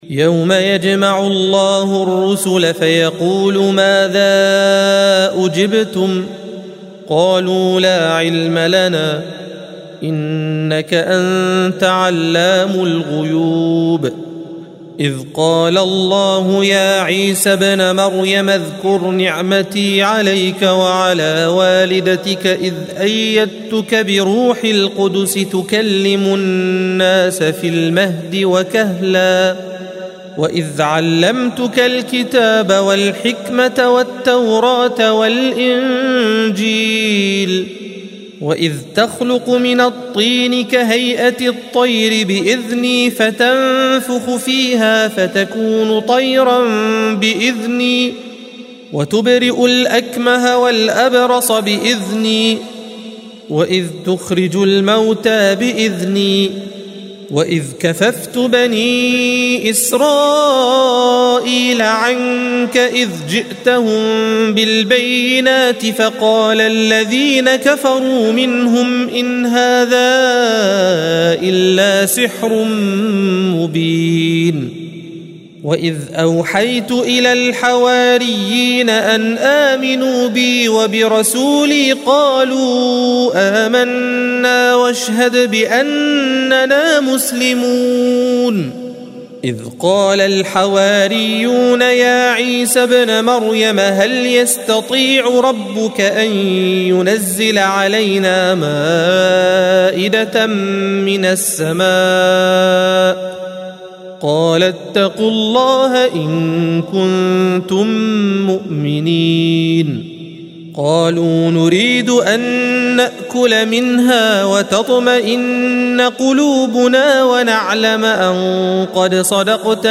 الصفحة 126 - القارئ